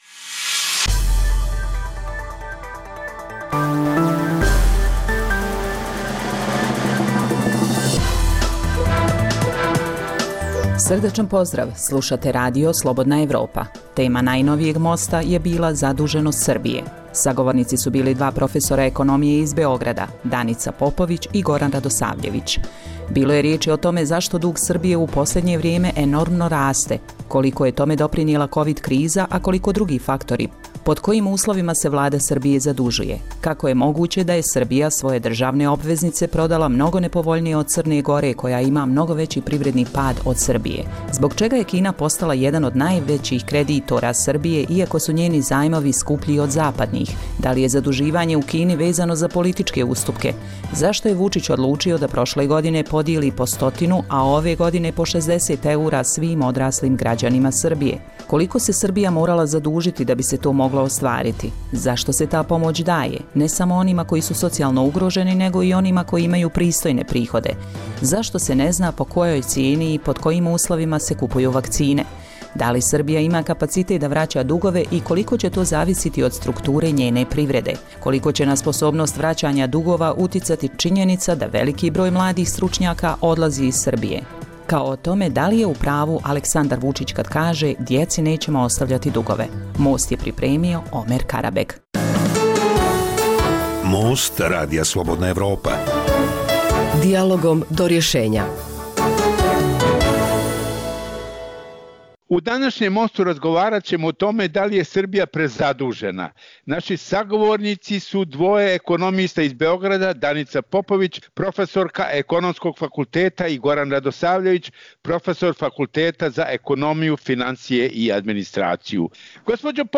Dijaloška emisija o politici, ekonomiji i kulturi koju uređuje i vodi